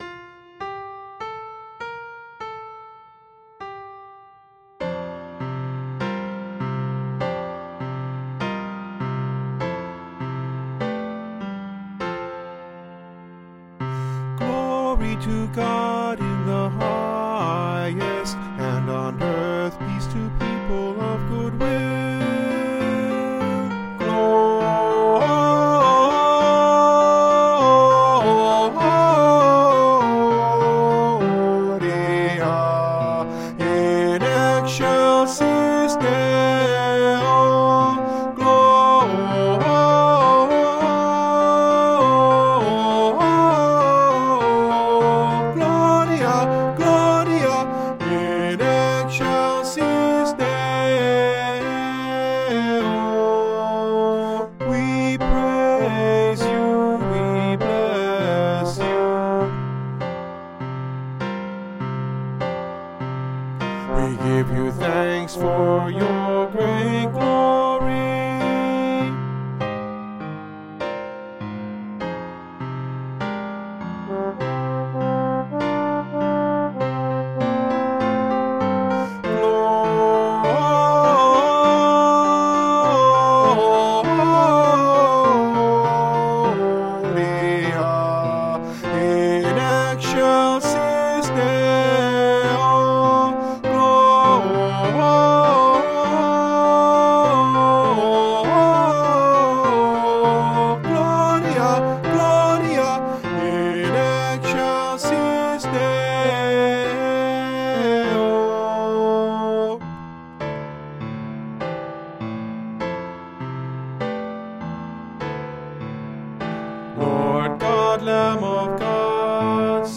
Tenor   Instrumental | Downloadable   Voice | Downloadable